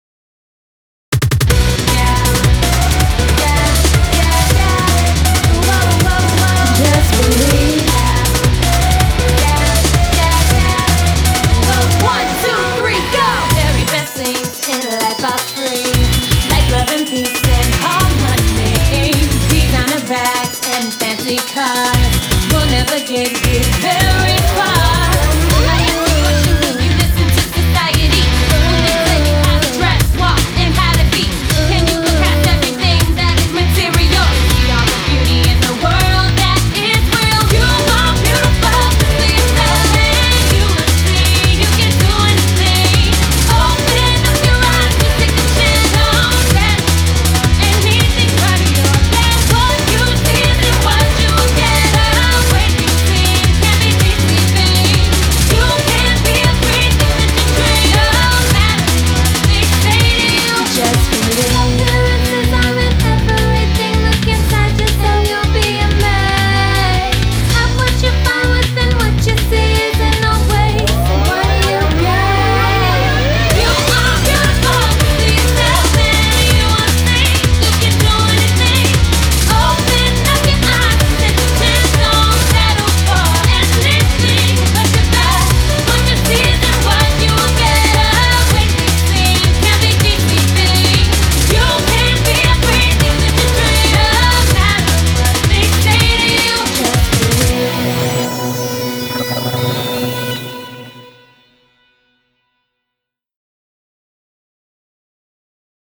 BPM160
Audio QualityLine Out